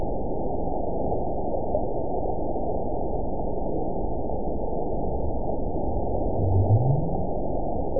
event 922633 date 02/10/25 time 05:12:30 GMT (2 months, 3 weeks ago) score 9.60 location TSS-AB02 detected by nrw target species NRW annotations +NRW Spectrogram: Frequency (kHz) vs. Time (s) audio not available .wav